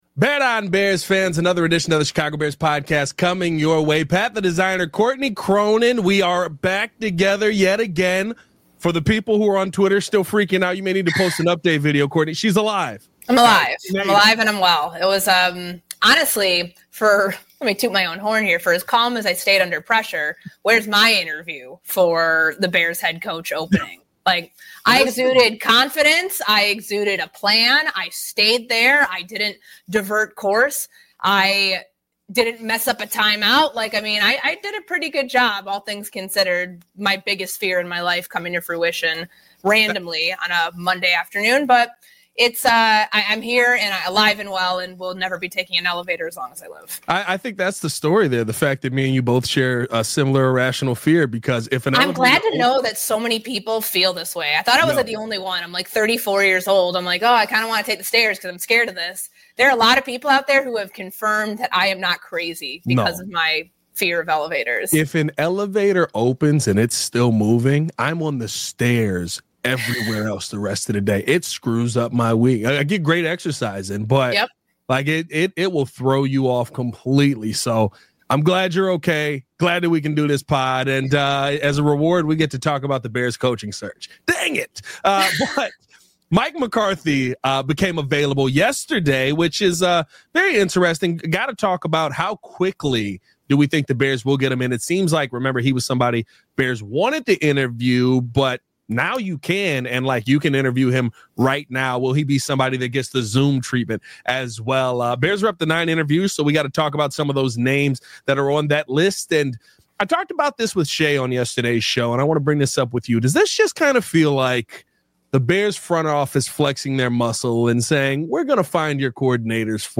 From his unique experience to his potential impact on the team’s culture, we explore why McCarthy could be a standout choice. Don’t miss this insightful discussion on the Bears’ coaching search!